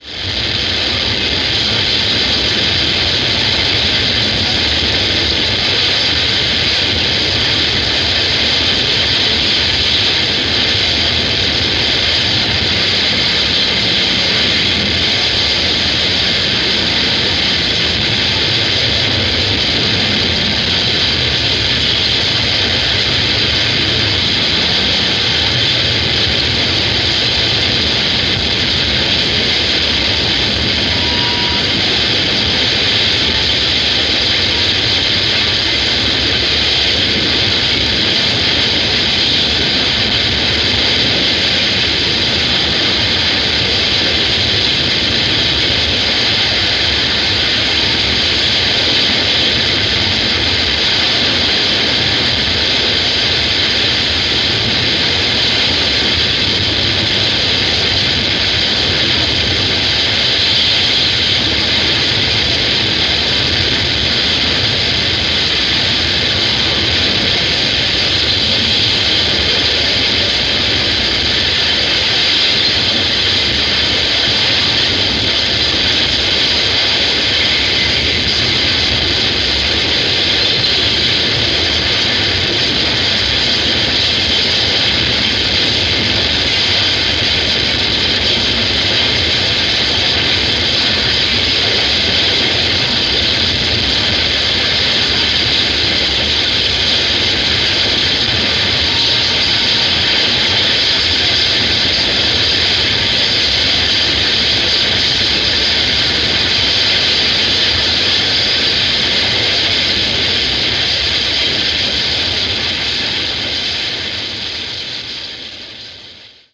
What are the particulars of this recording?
The venue was well past its capacity.